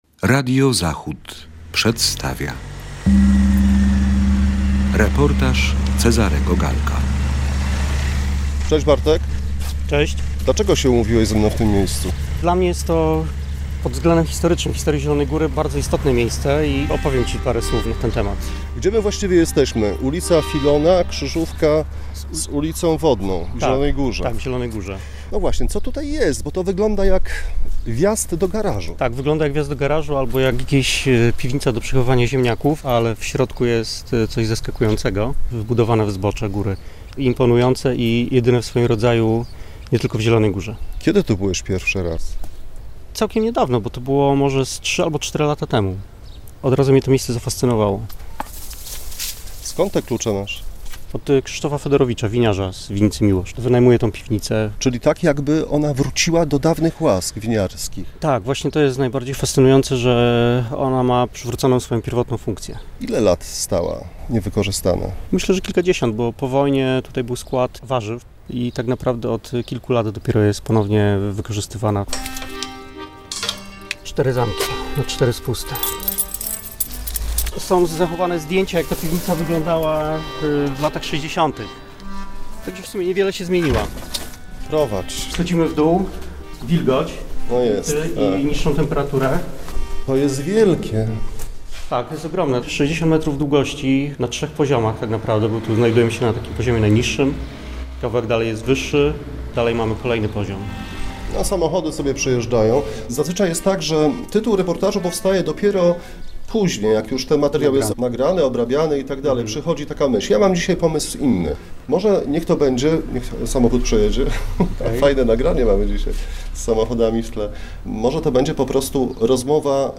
Spotkaliśmy się w miejscu niezwykłym – jednej z wielu nieznanych szerzej współczesnym mieszkańcom miasta Zielonej Góry winiarskiej piwnic, która wcinając się w zbocze góry, zaskakuje rozmiarami i ciekawi historią.